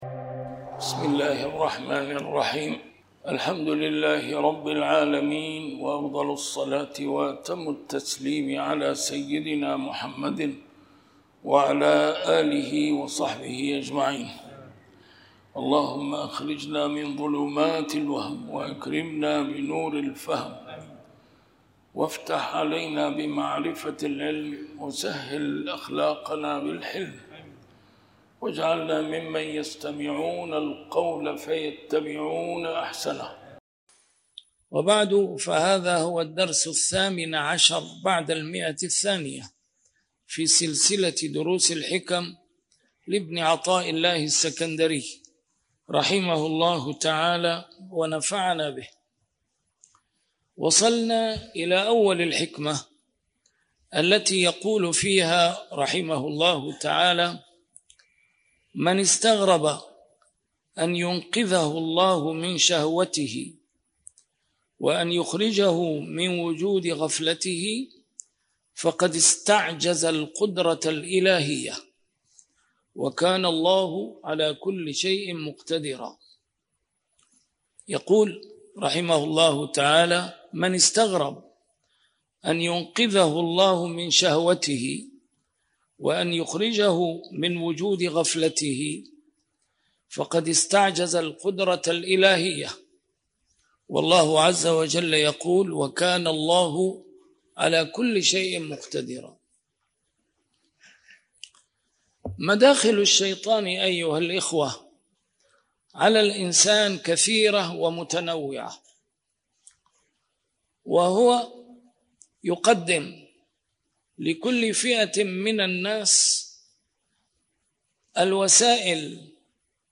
A MARTYR SCHOLAR: IMAM MUHAMMAD SAEED RAMADAN AL-BOUTI - الدروس العلمية - شرح الحكم العطائية - الدرس رقم 218 شرح الحكمة رقم 197